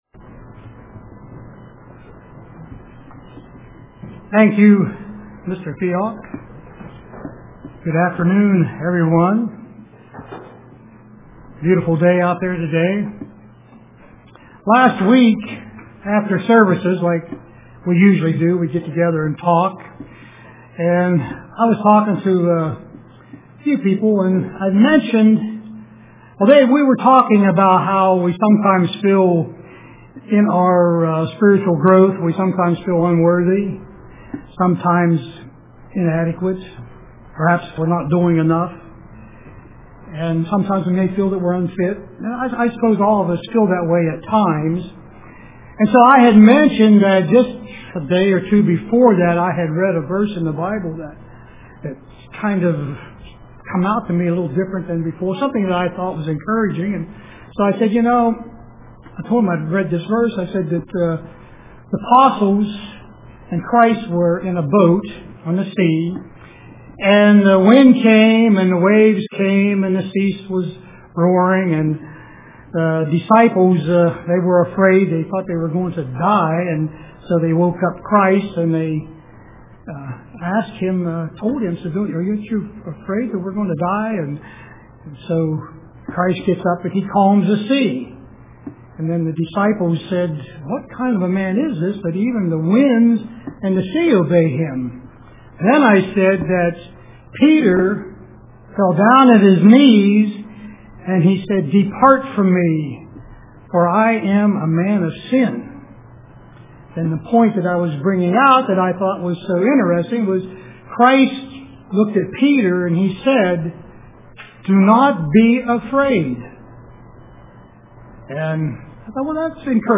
Print A Slip of the Mind UCG Sermon